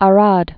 (ä-räd)